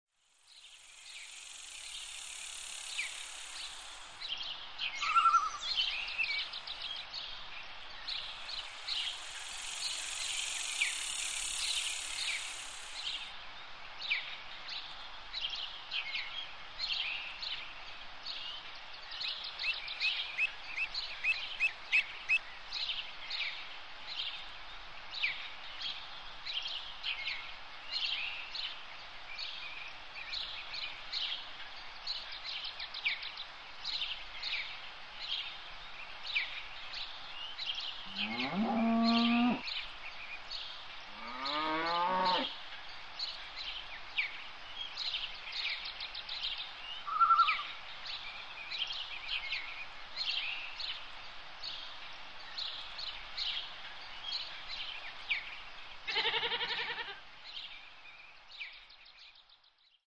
Woodland Bridalway
Category: Animals/Nature   Right: Personal